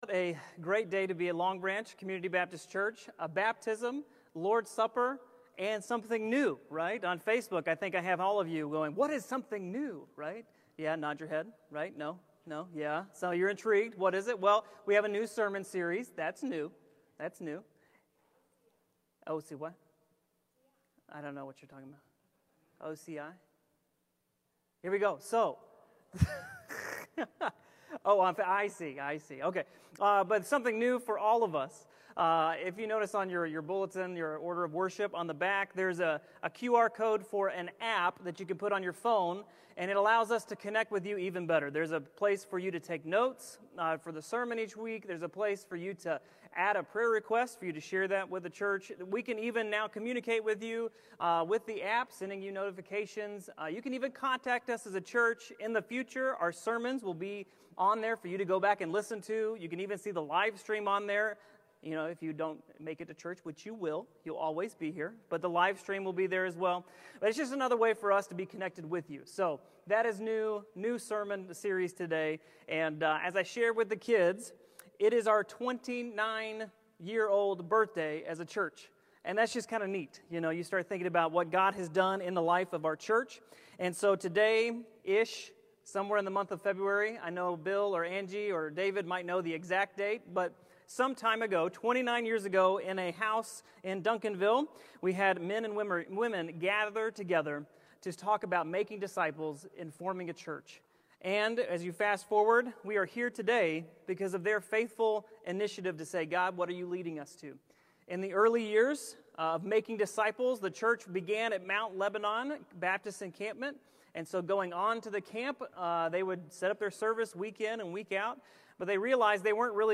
Sermons | Longbranch Community Baptist Church